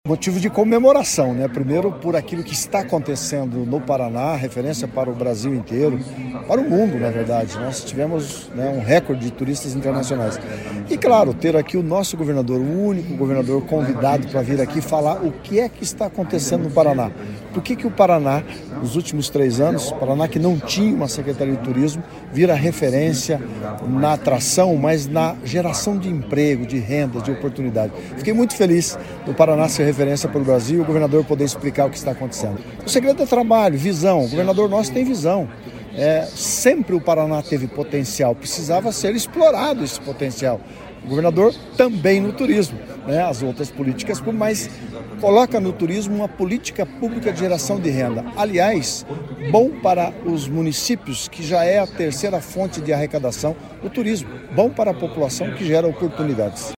Sonora do secretário do Turismo, Leonaldo Paranhos, sobre o 23º Fórum PANROTAS 2026